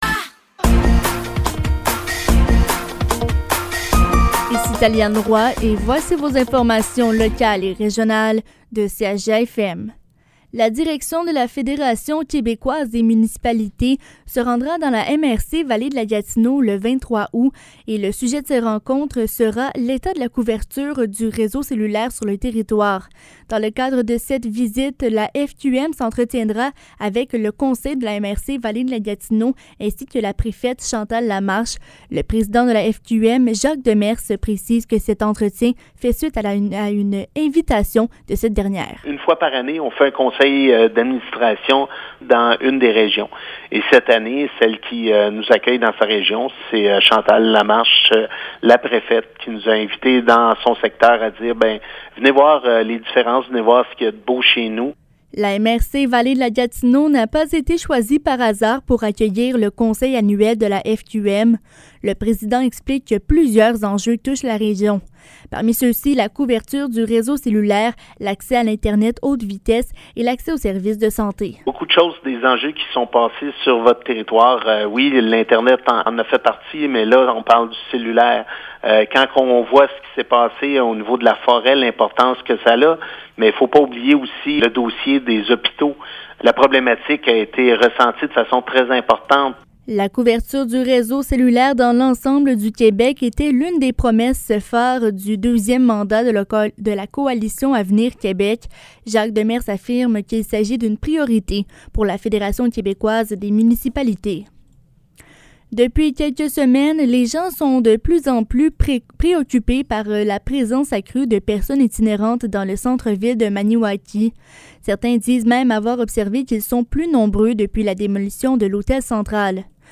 Nouvelles locales – 7 août 2023 – 15 h